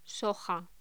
Locución: Soja
voz